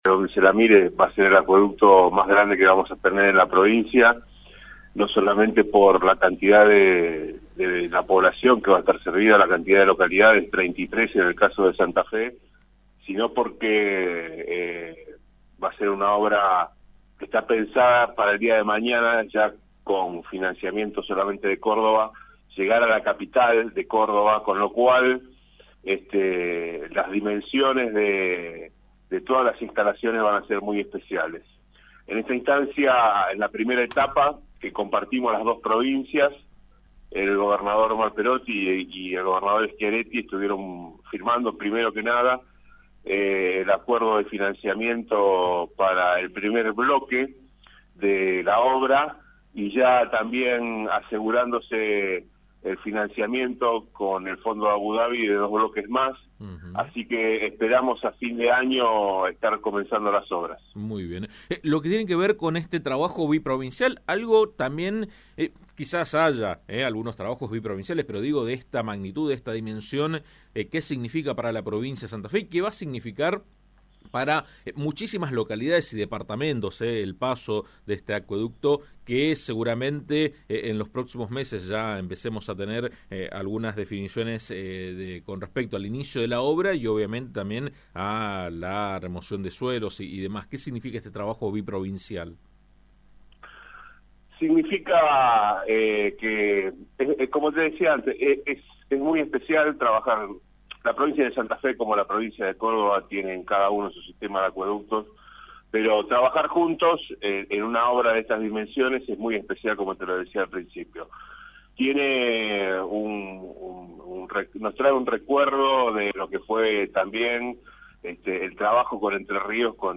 AM 1330 habló con el secretario de Empresas y Servicios Públicos de Santa Fe, Carlos Maina, sobre los detalles de la obra, las obras complementarias, el financiamiento y las localidades que serán beneficiadas.